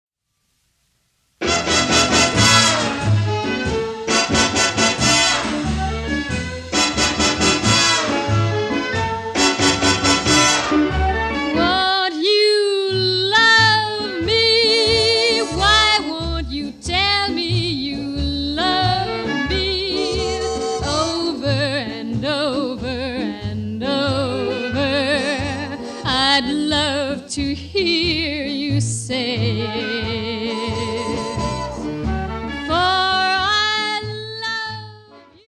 female vocalists
Canadian popular and jazz music